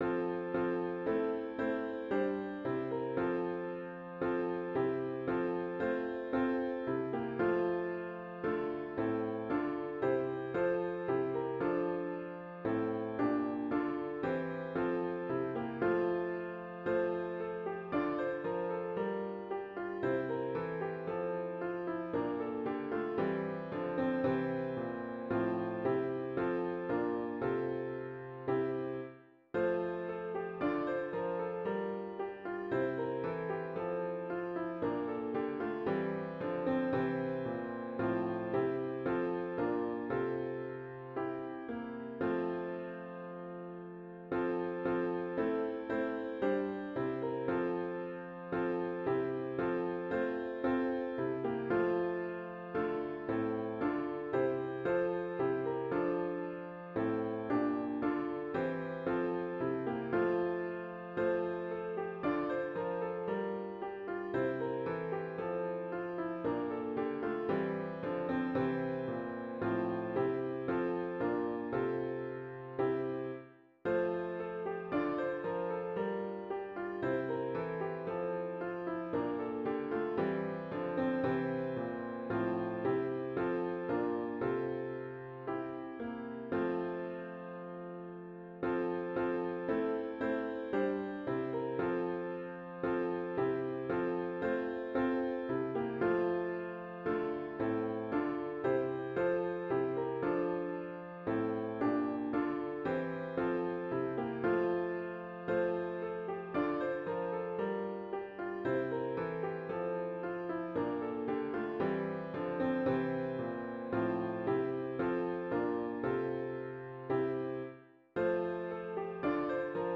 *HYMN “Joy to the World” GtG 134